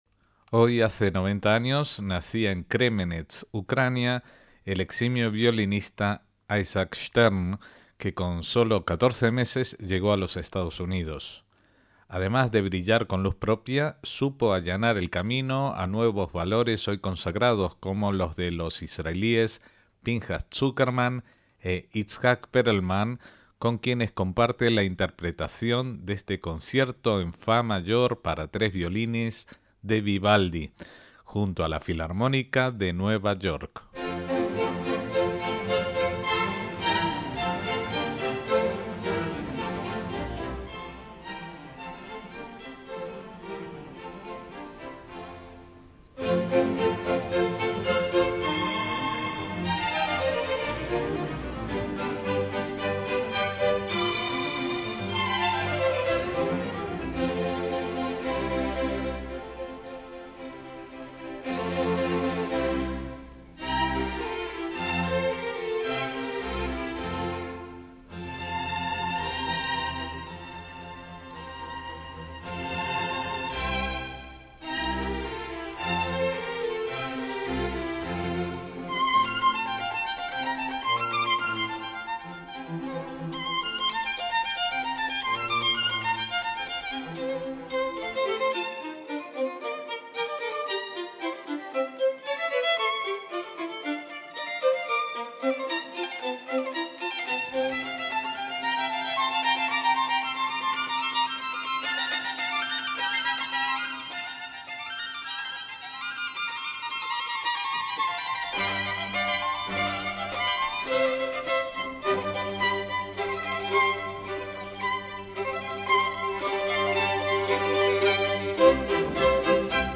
A 280 años de la muerte de Vivaldi, su concierto para tres violines con Stern, Perlman y Zuckerman
MÚSICA CLÁSICA